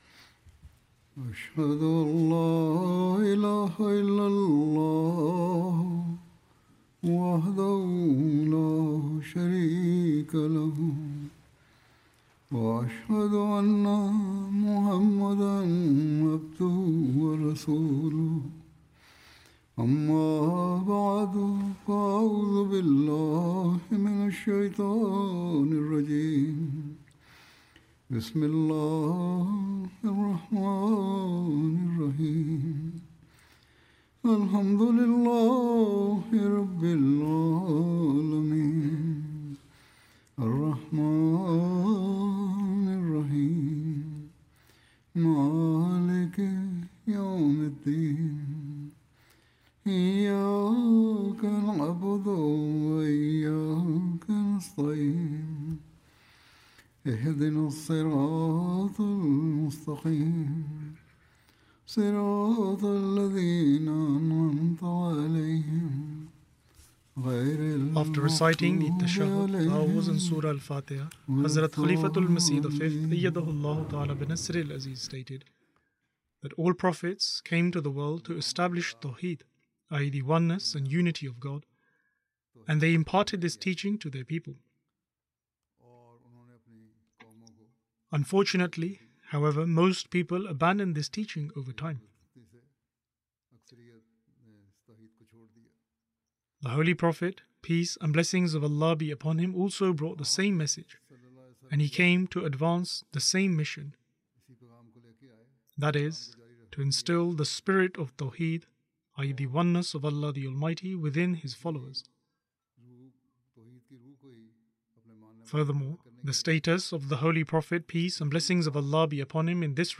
English translation of Friday Sermon delivered by Khalifa-tul-Masih on February 27th, 2026 (audio)